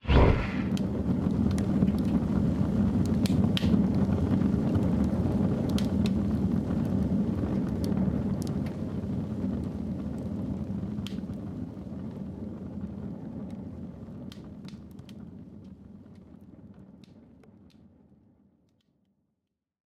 create-bonfire-001.ogg